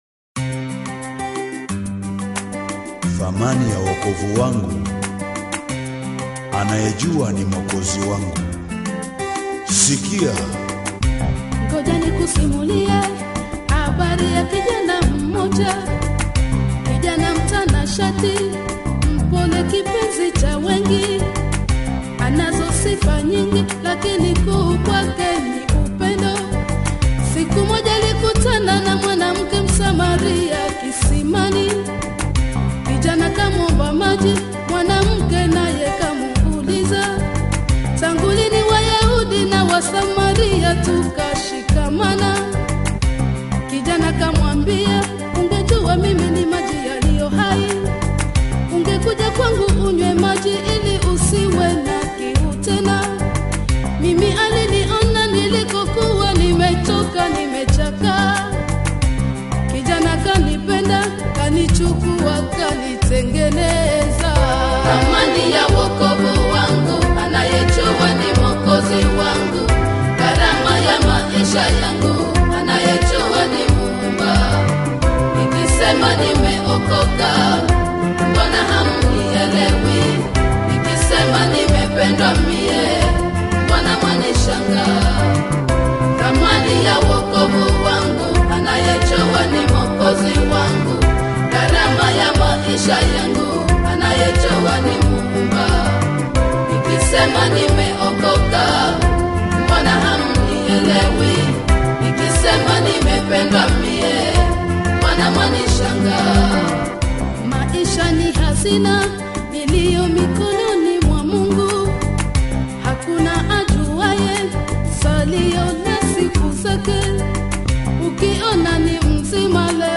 gospel
Both praise and worship songs.